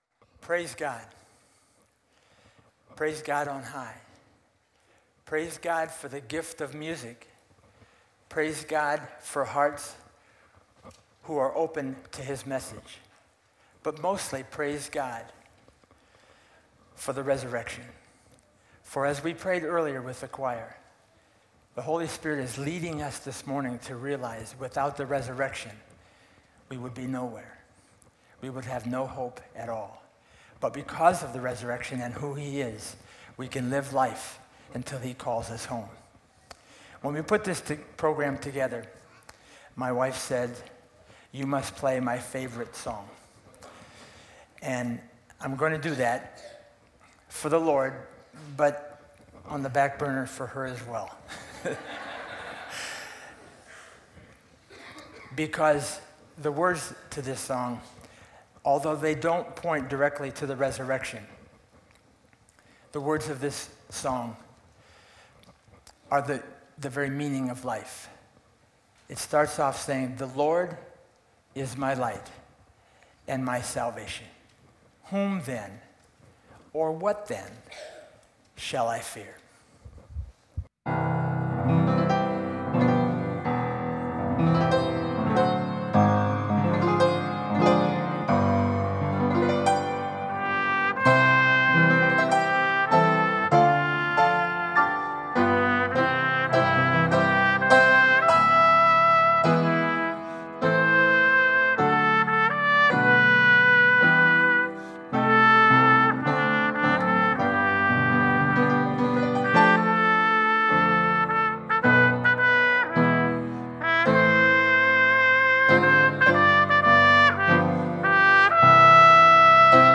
Sunday Morning Music
Trumpet Solo - The Lord Is My Light